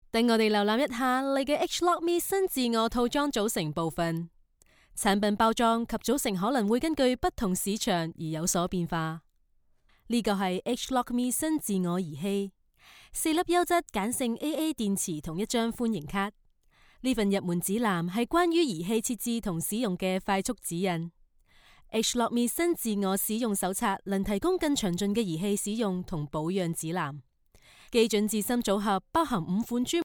Female
Natural, engaging, authentic, with strong professional delivery
E-Learning